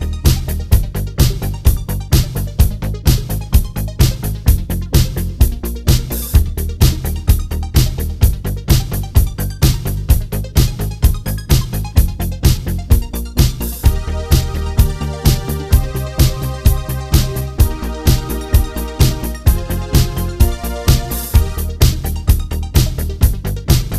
Two Semitones Down Cut Down Disco 3:39 Buy £1.50